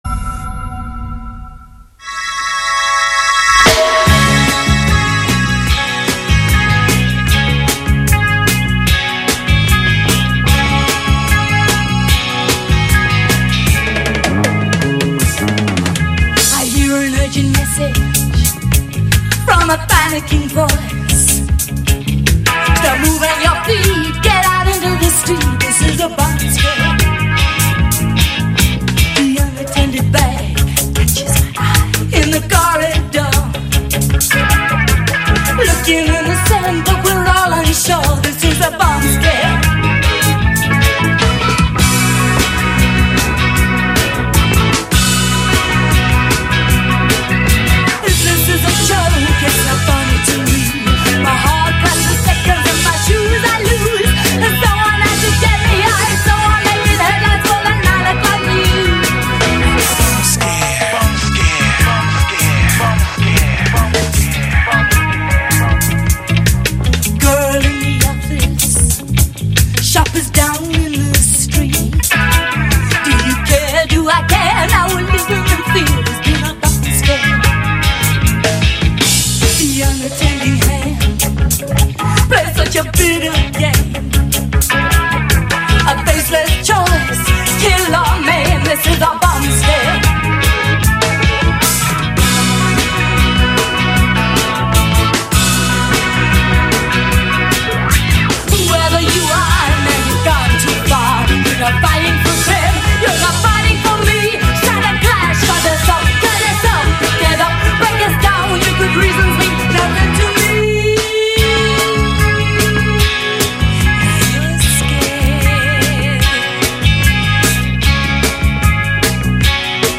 Vos esgourdes seront abreuvées de 50’s Rockabilly, Country blues, Blues, R&B, 60’s, Garage, Rock, Surf et autres morceaux instrumentaux.